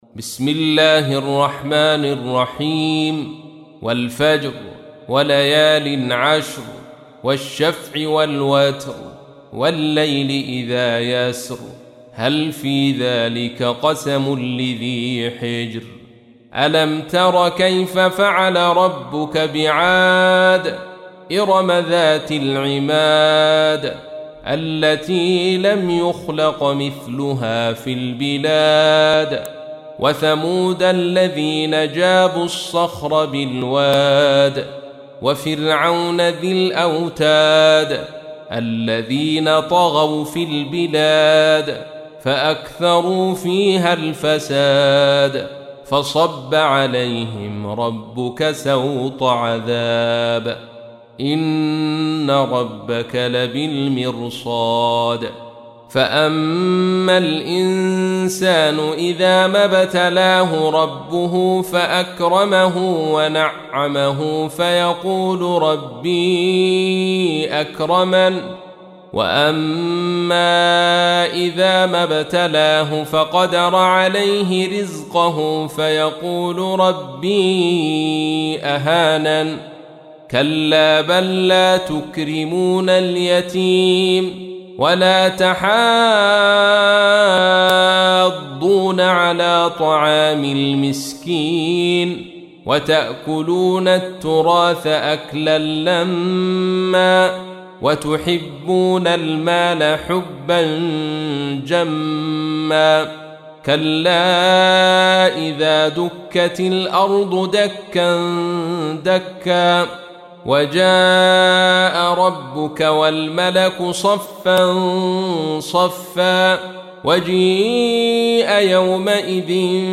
تحميل : 89. سورة الفجر / القارئ عبد الرشيد صوفي / القرآن الكريم / موقع يا حسين